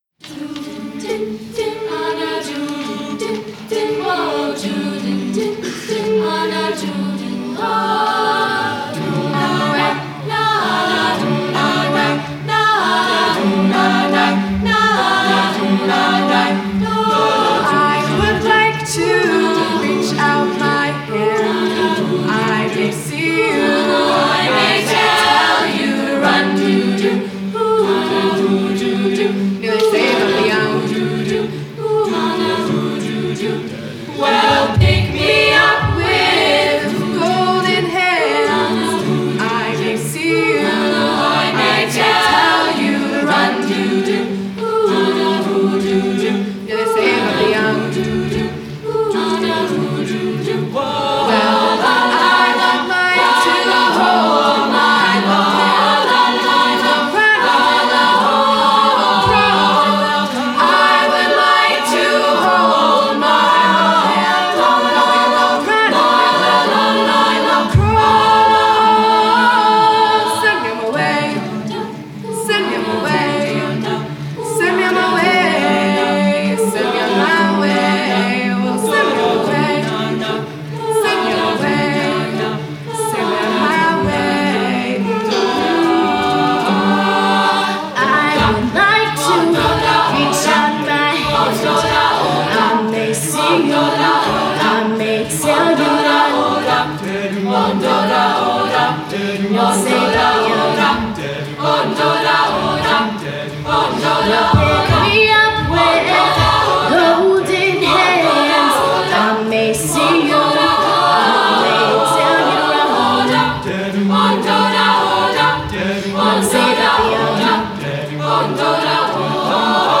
Spring Music Festival
06-A-Cappella-Choir-Send-Me-On-My-Way.mp3